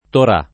Torà [ tor #+ ]